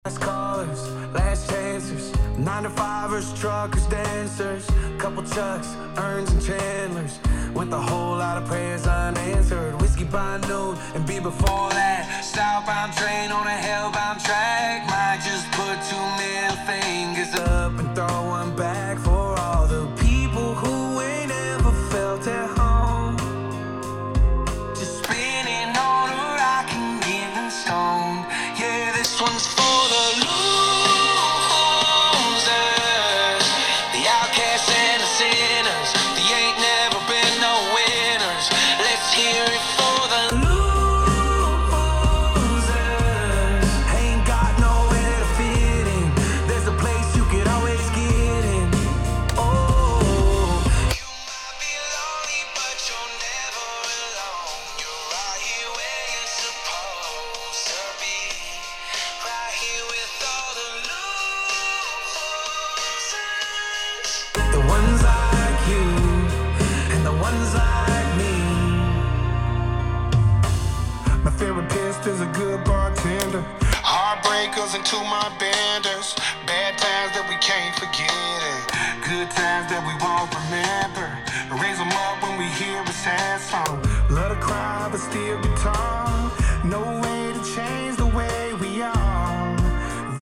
Опять же, в нормально заглушенной комнате Вы не будете вваливать, тем самым разгоняя отражения, особенно по низу ( о чем постоянно говорят отцы форума) В примере максимальная комфортная громкость 75-80 dB, средняя меньше 70.
Вложения 4 линии мониторов.mp3 4 линии мониторов.mp3 3,4 MB